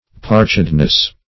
Parchedness \Parch"ed*ness\, n. The state of being parched.